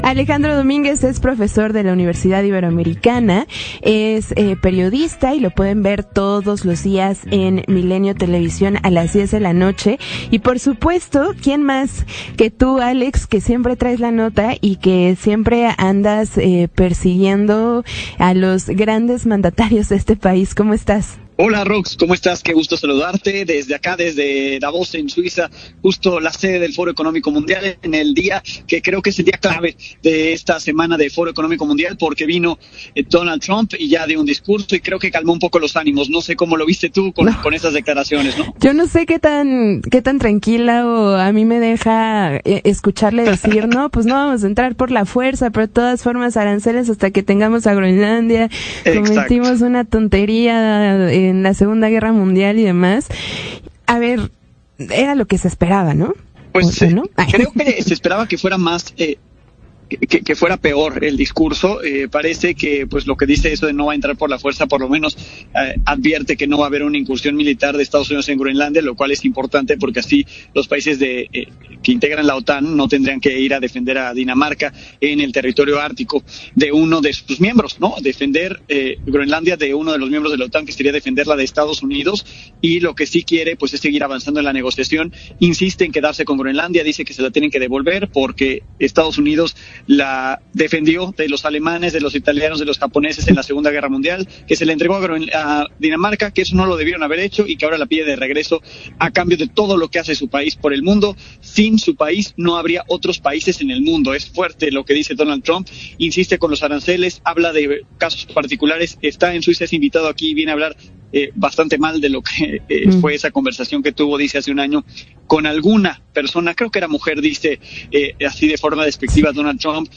En entrevista desde Davos para Tengo Otros Datos